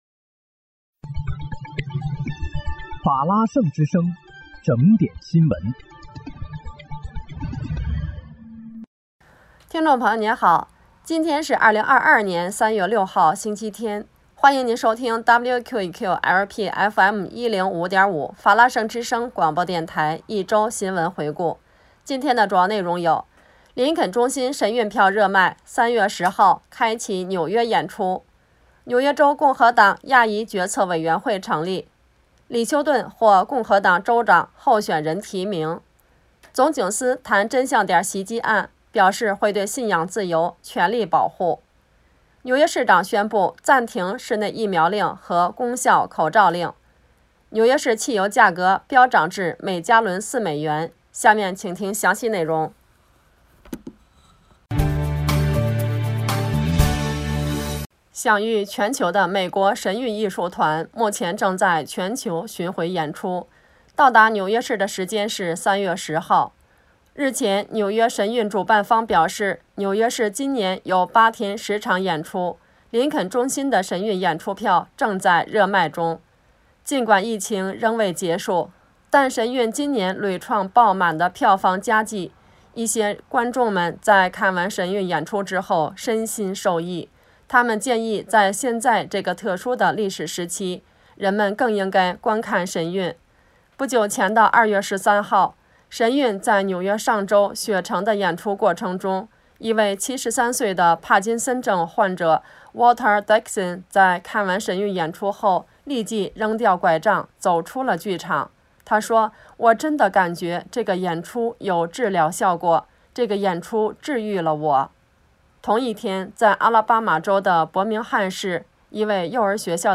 3月6日（星期日）一周新闻回顾